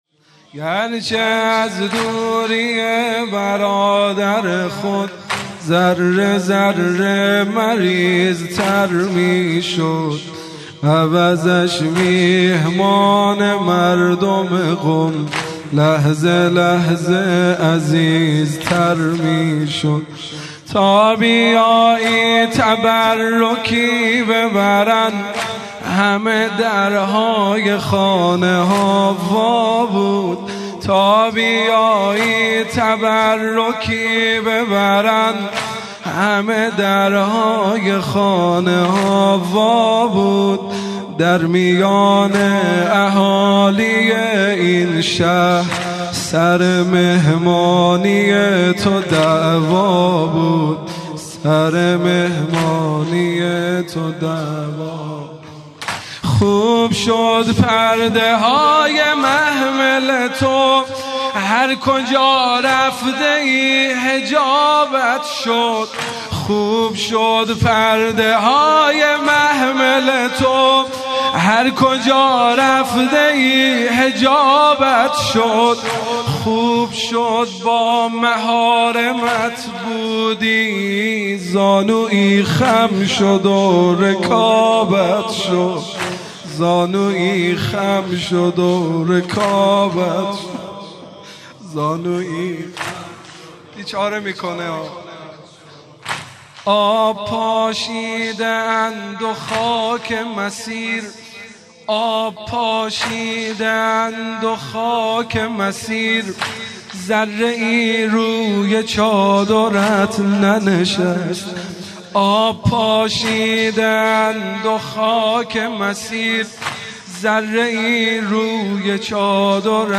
مداحی سوزناک